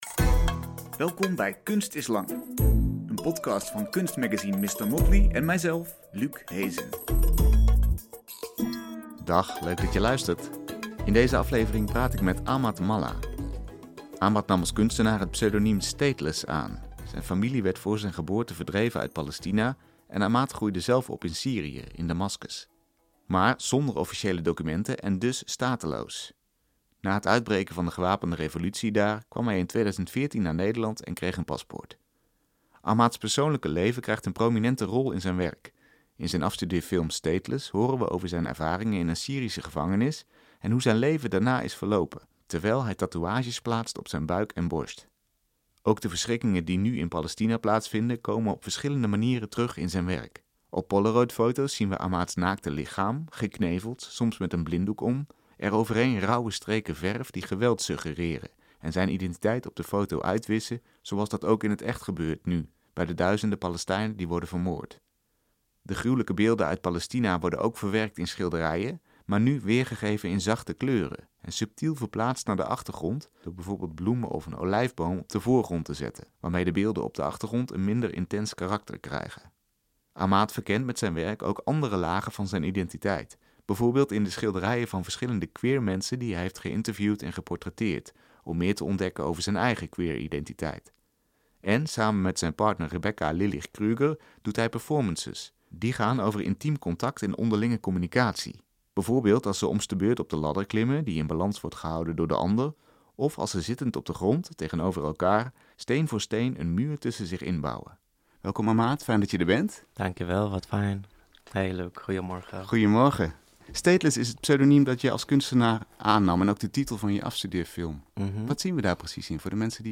1 DAS GEHEIMNIS DER TRANSZENDENTALEN MEDITATION 53:24 Play Pause 11h ago 53:24 Play Pause เล่นในภายหลัง เล่นในภายหลัง ลิสต์ ถูกใจ ที่ถูกใจแล้ว 53:24 Regisseur David Lynch schwört drauf, die Beatles haben es berühmt gemacht. Transzendentale Meditation soll nicht nur innere Ruhe bringen und die eigene Kreativität steigern, sondern sogar den Weltfrieden herbeiführen sollen. Heute erfahren wir mehr im Gespräch